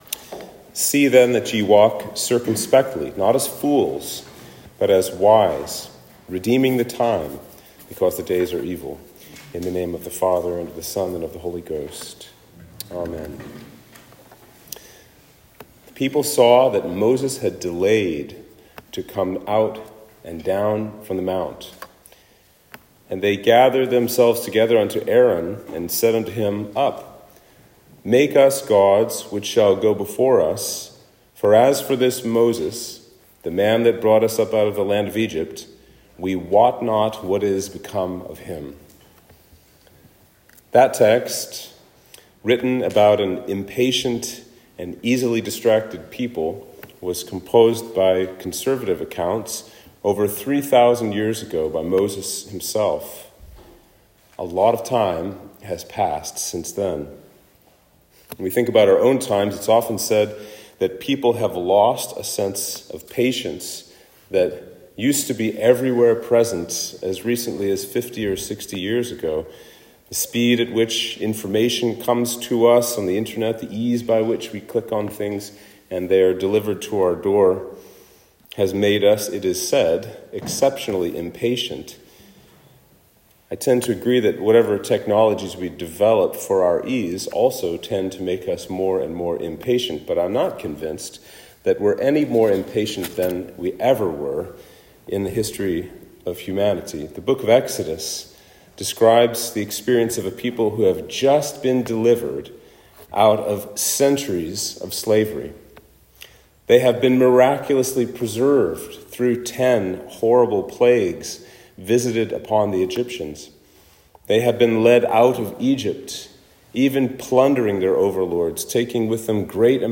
Sermon for Trinity 20